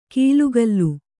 ♪ kīlugallu